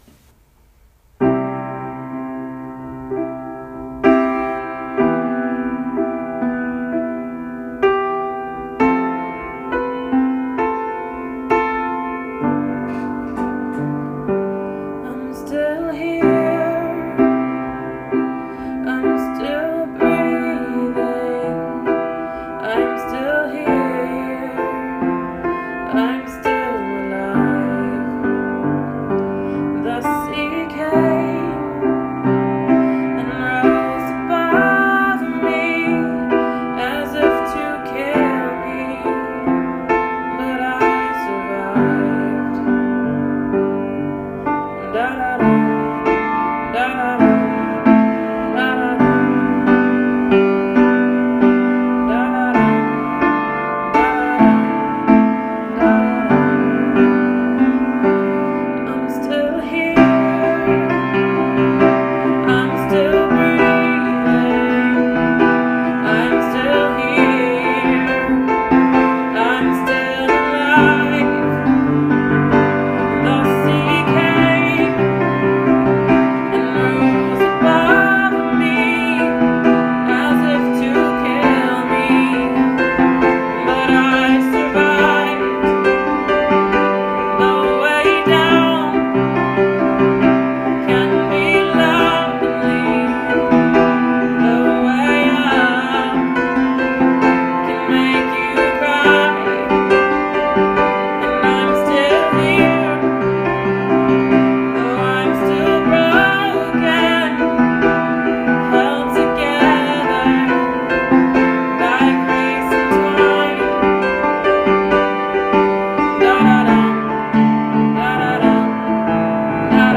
😉 Anyway, until I get around to making decent recordings, I’m gonna try to make more time to post my semi-decent iPhone recordings.